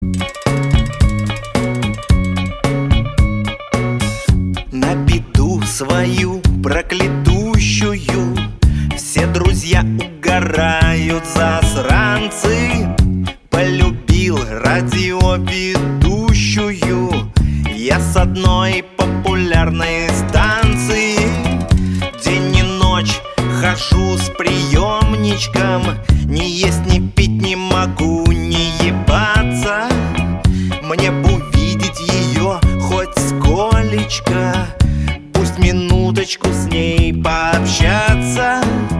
смешные